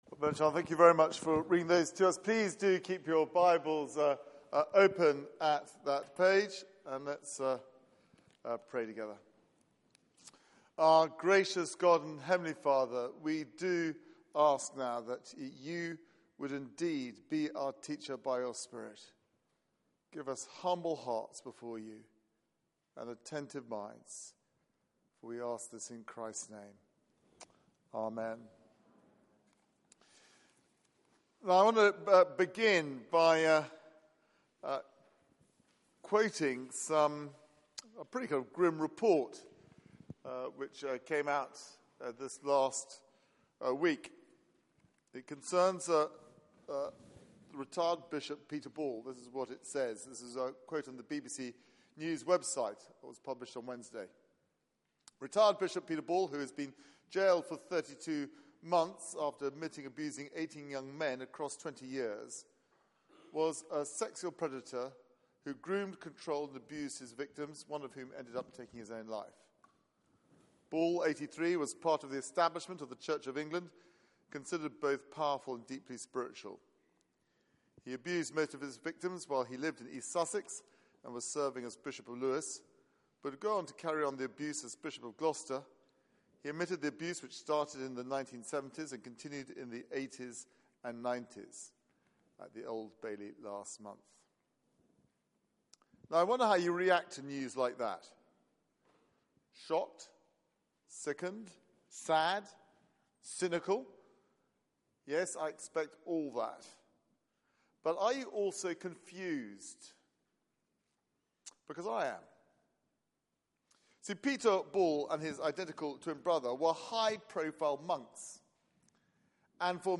Passage: 1 John 2:28-3:10 Service Type: Weekly Service at 4pm